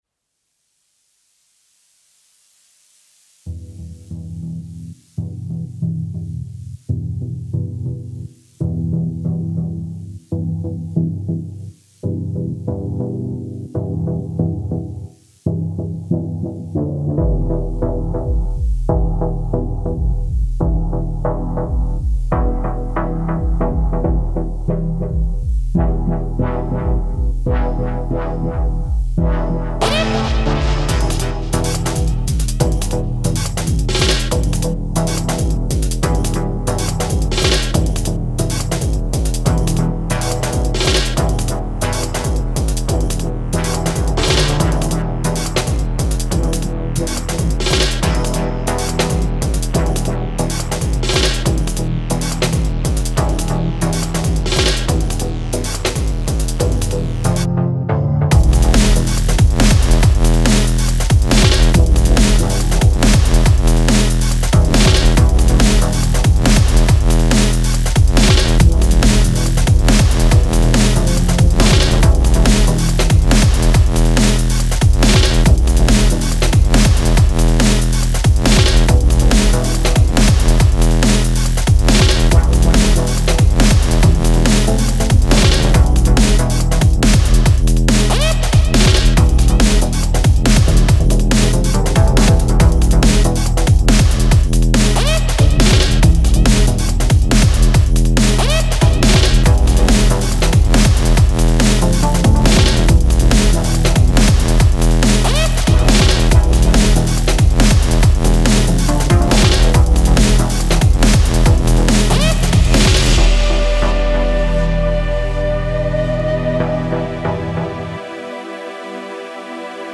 Style: Big Beat / Breakbeat / Dub